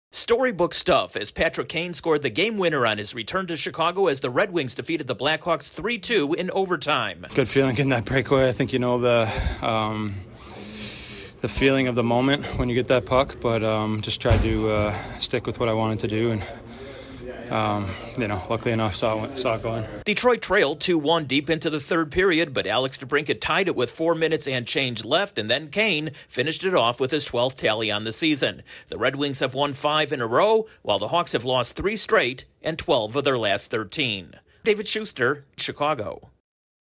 It's a memorable night for a former Blackhawk in Chicago. Correspondent